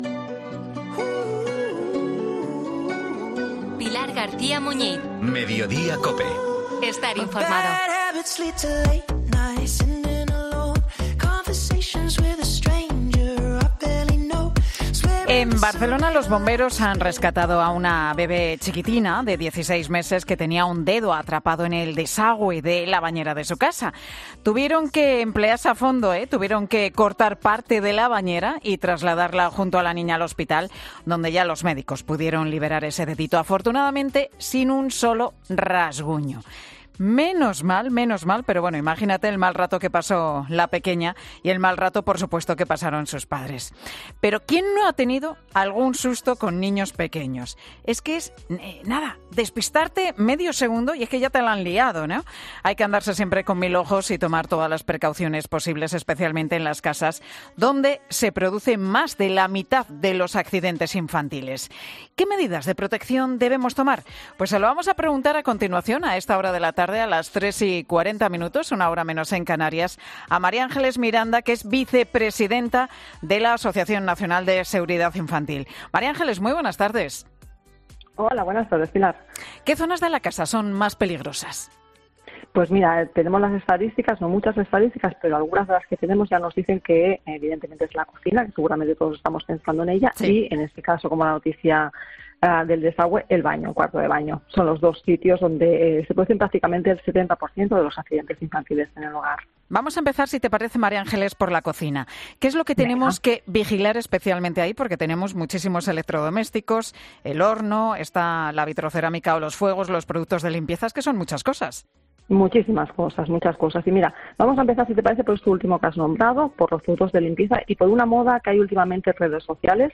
Mediodía COPE